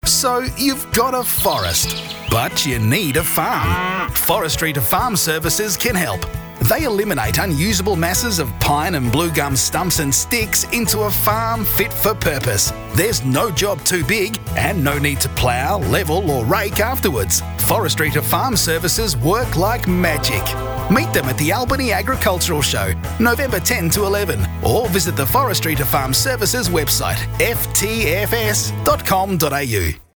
This urgent project included scriptwriting, selecting an appropriate voice-over artist, and recording the commercial.